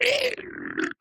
Minecraft Version Minecraft Version 1.21.5 Latest Release | Latest Snapshot 1.21.5 / assets / minecraft / sounds / mob / strider / death2.ogg Compare With Compare With Latest Release | Latest Snapshot
death2.ogg